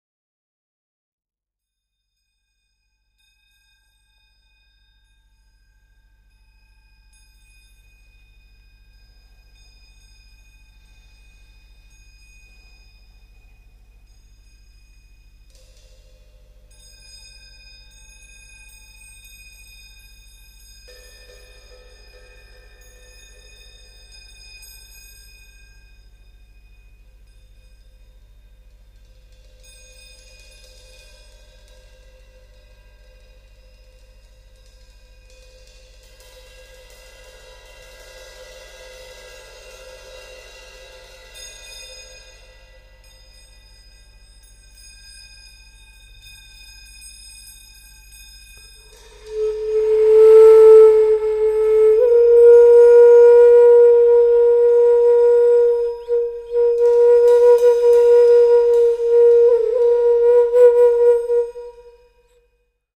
at studio Voice
轆轤笛
明珍火箸
平太鼓
シンバル